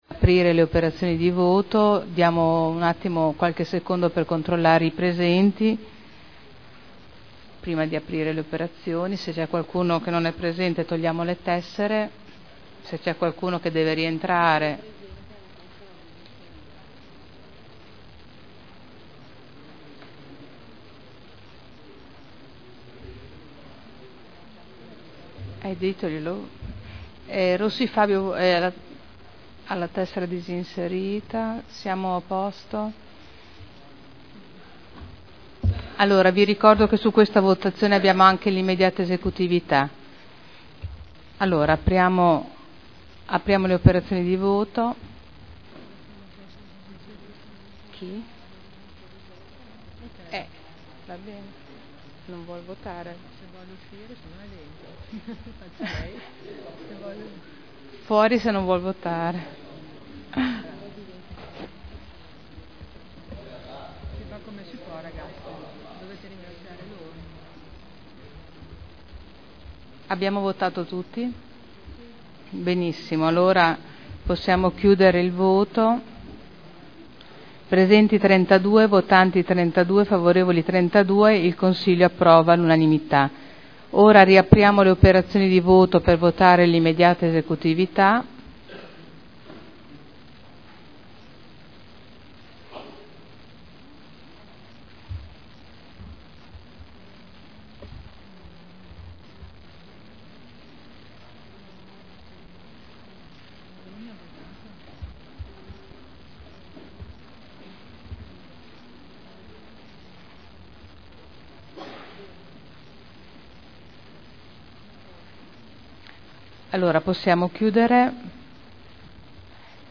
Seduta del 19/09/2011. Mette ai voti proposta di deliberazione e immediata esecutività.